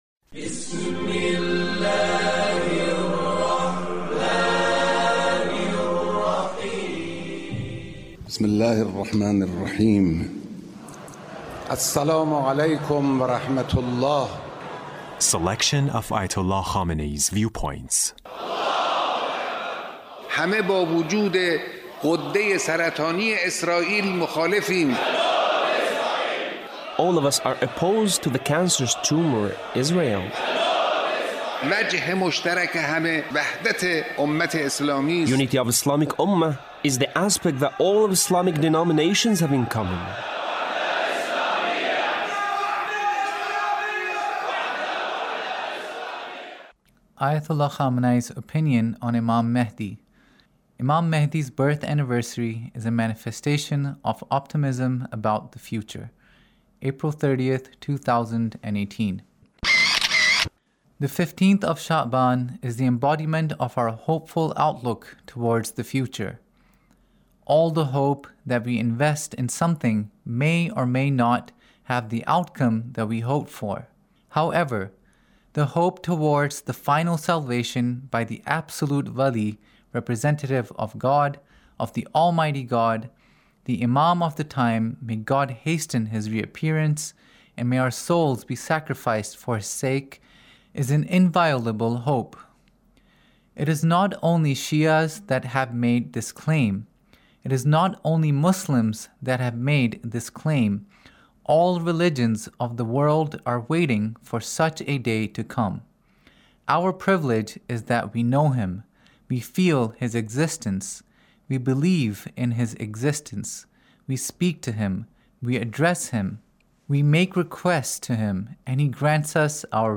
Leader's Speech on Mahdawiyya